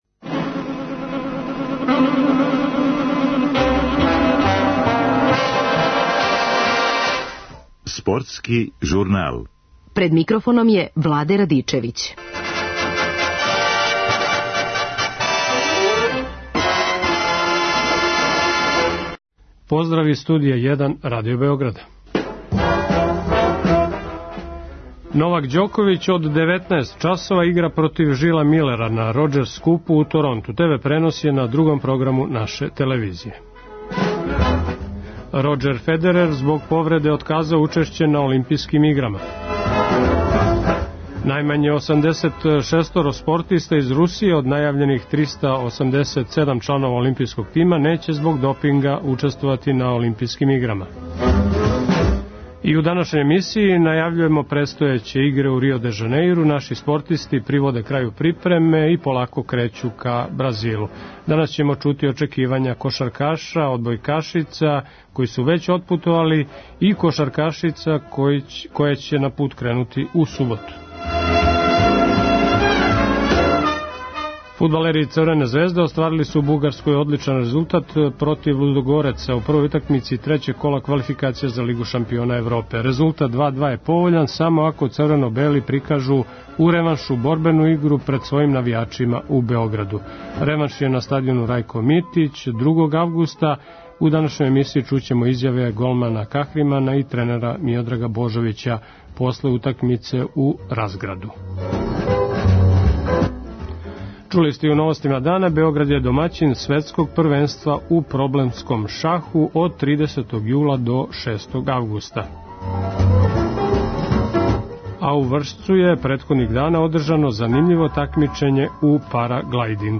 Данас ћемо чути очекивања кошаркаша, одбојкашица и кошаркашица.